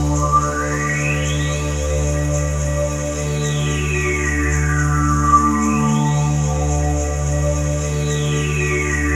DM PAD1-12.wav